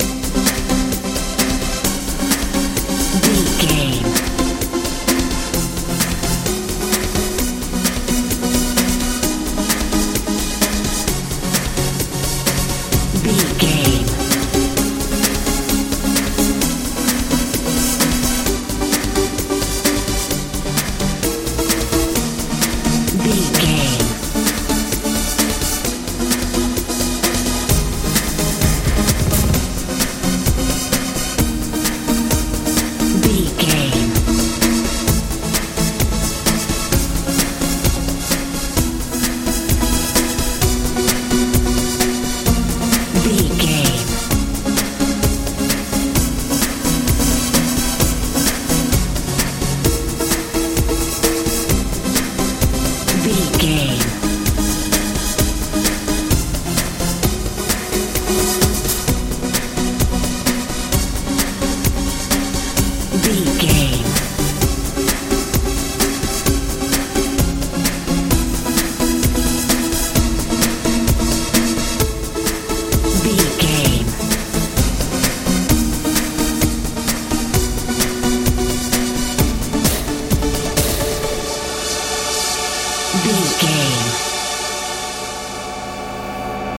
modern dance feel
Ionian/Major
Fast
happy
fun
bass guitar
drums
synthesiser
80s
90s
tension
ominous